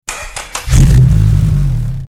Car Engine Start
Car_engine_start.mp3